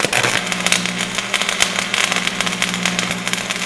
welderhit.wav